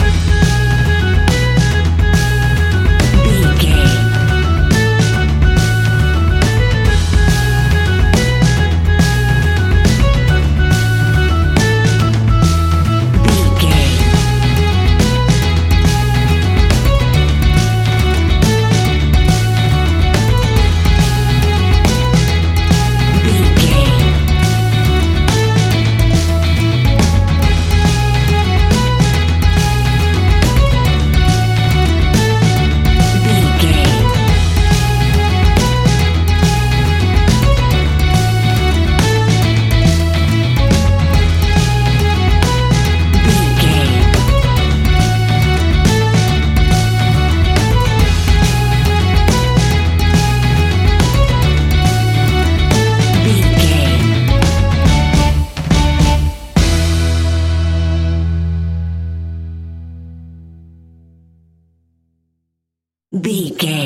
Ionian/Major
acoustic guitar
mandolin
double bass
accordion